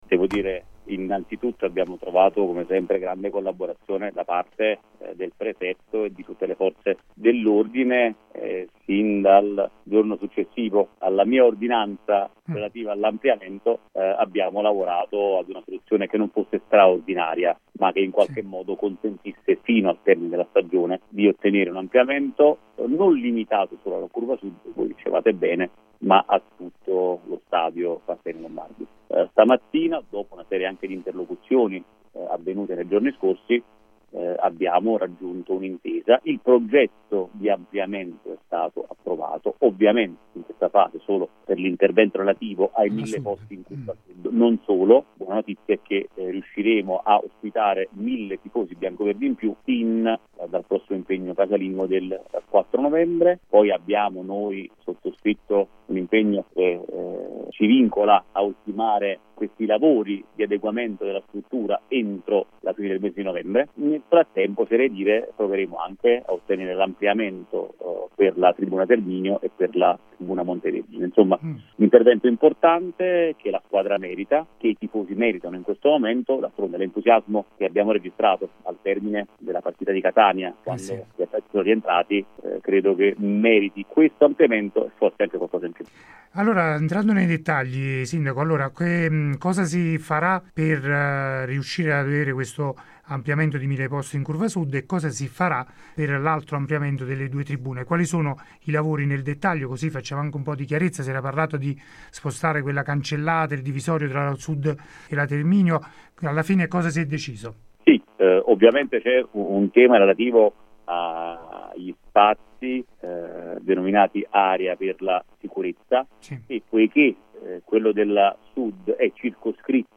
Ospite de Il Pomeriggio da Supereroi di Radio Punto Nuovo, il sindaco di Avellino Gianluca Festa. Il primo cittadino ha illustrato ai nostri microfoni tutti i dettagli per l’ampliamento dello Stadio Partenio-Lombardi che, a partire dalla gara in programma sabato alle 18:30 contro la Virtus Francavilla, potrà contare su ulteriori mille posti in Curva Sud.